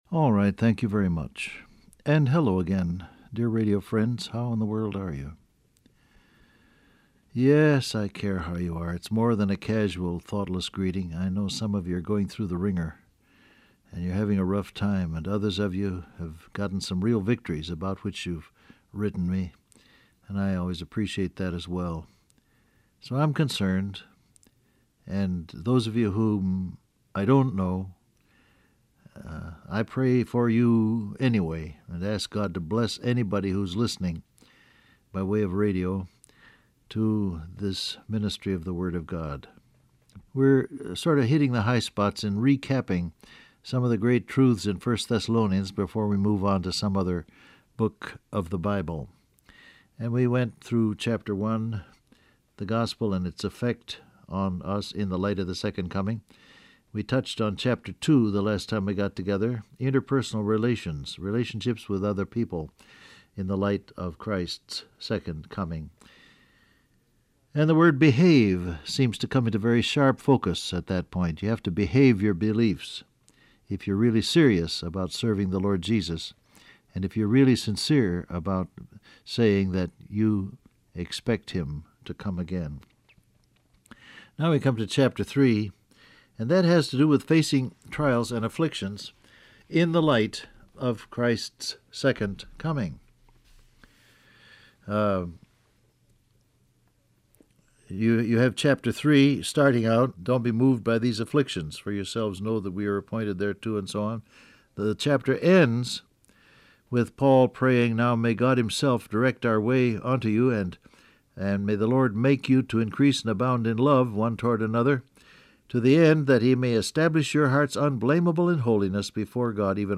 Download Audio Print Broadcast #7113 Scripture: 1 Thessalonians 3 Topics: Trials , Caring , Encouragement , Love For Others Transcript Facebook Twitter WhatsApp Alright, thank you very much.